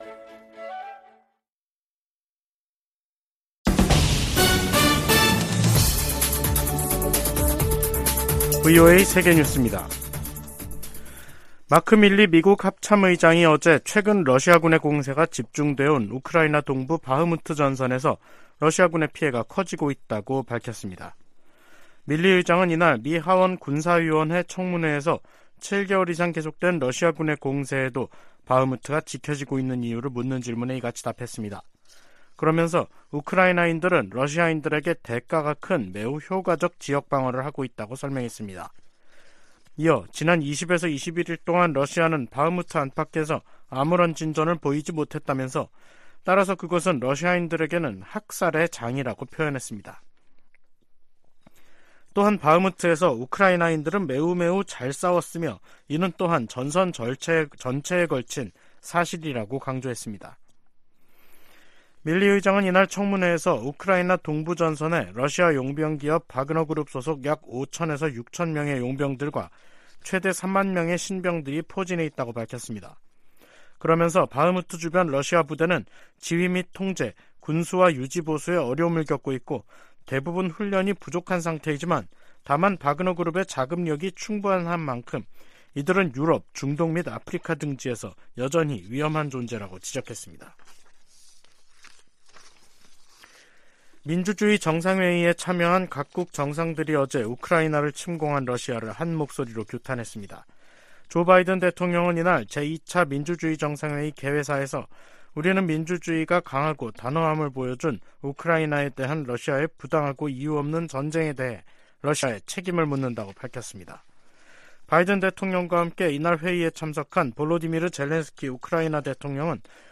VOA 한국어 간판 뉴스 프로그램 '뉴스 투데이', 2023년 3월 30일 2부 방송입니다. 조 바이든 미국 대통령과 윤석열 한국 대통령은 제2차 민주주의 정상회의에서 공동성명을 내고 양국은 공동의 민주적 가치와 인권 존중을 기반으로 깊은 유대를 공유하고 있다고 밝혔습니다. 미 국무부는 한반도 비핵화 의지를 거듭 확인했습니다. 미국 공화당 중진 상원의원이 한국과의 핵 연습을 확대해야 한다고 주장했습니다.